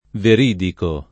DOP: Dizionario di Ortografia e Pronunzia della lingua italiana
veridico [ ver & diko ]